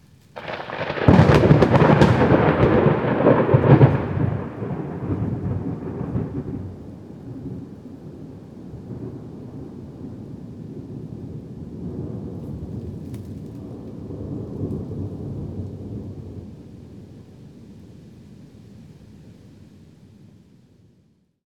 thunder_strike2.ogg